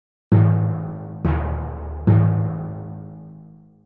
Скачать звуки литавры бесплатно и слушать онлайн - Zvukitop